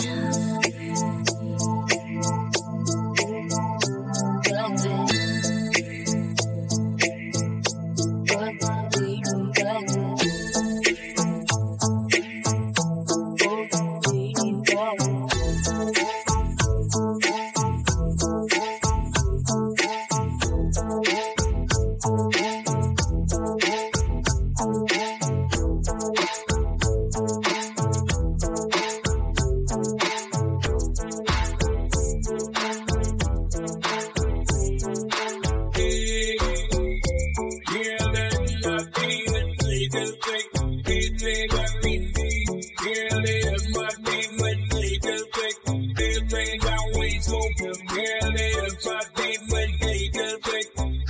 riffusion-app - Stable diffusion for real-time music generation (web app)
techno_to_jamaican_rap.mp3